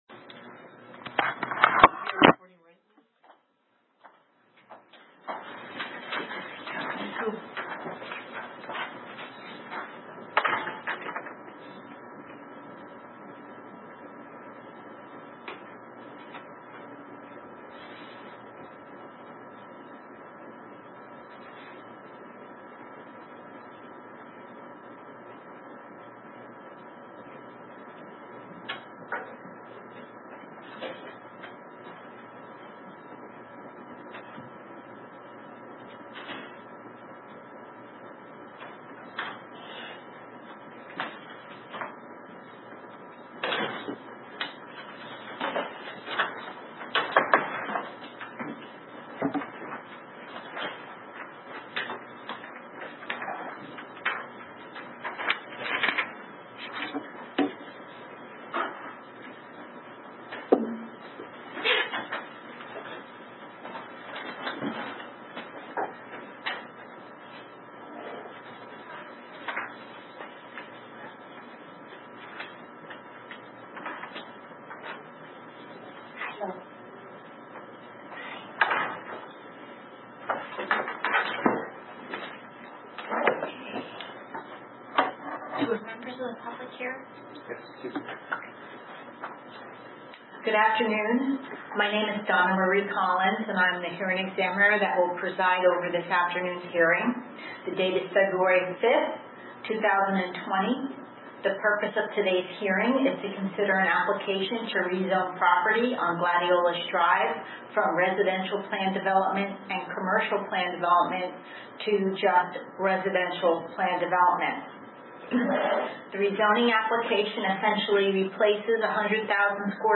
RECORDING OF THE FEBRUARY 5, 2020 HEARING FOR AMAVIDA RPD